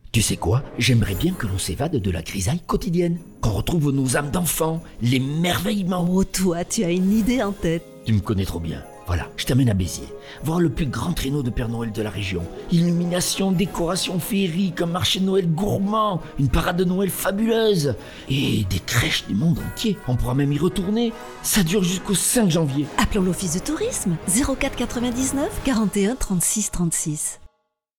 Spot-radio-Beziers-Decembre-2024-ok.mp3